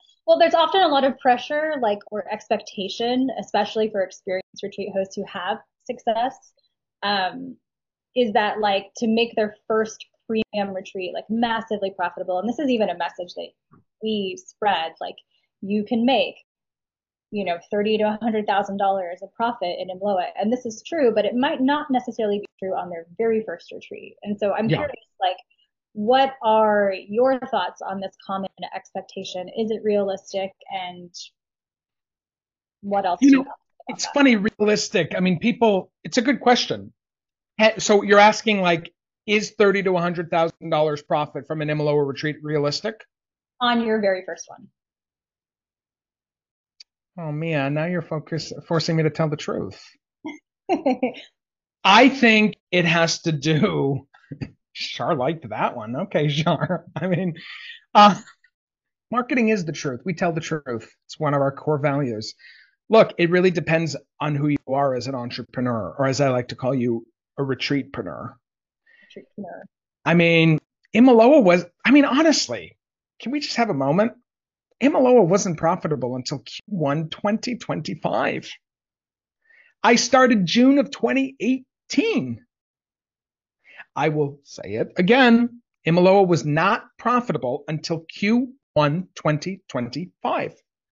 📍 Live on Zoom